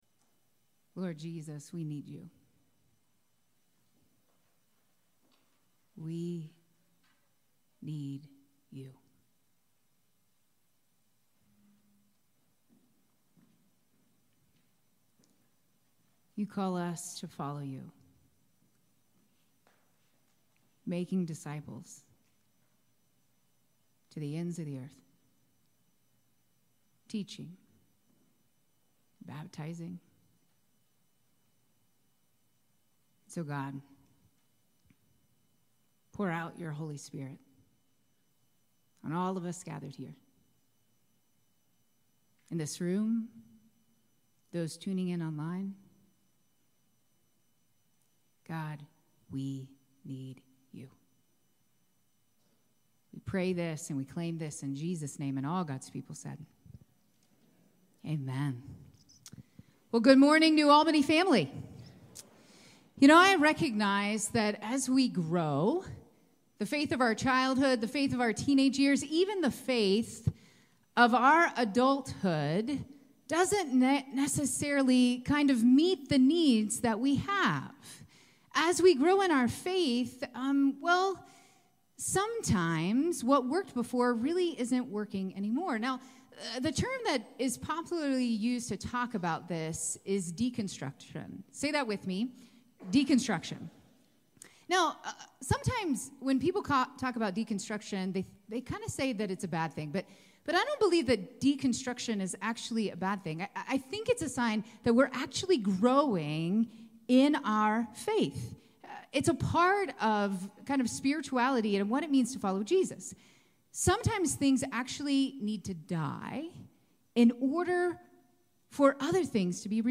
9:30 AM Redemption Worship Service 02/02/25 – New Albany United Methodist Church